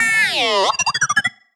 sci-fi_driod_robot_emote_neg_02.wav